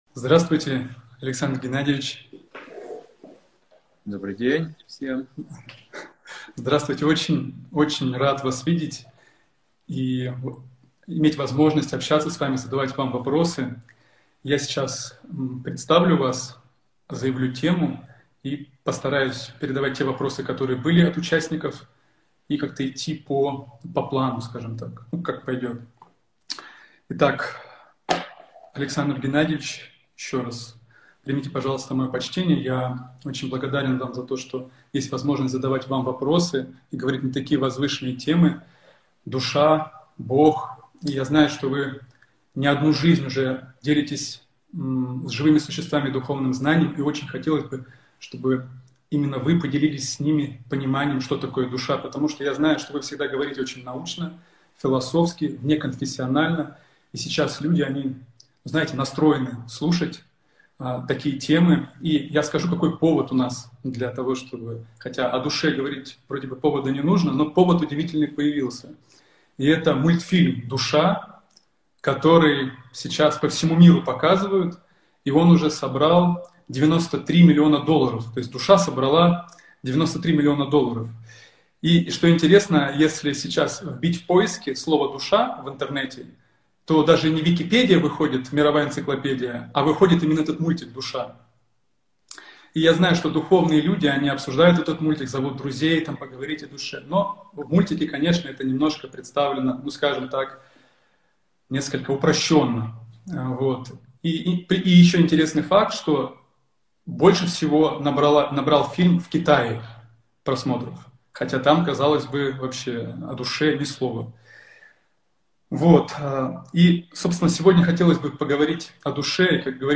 Алматы